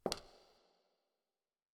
heel-reverb4.wav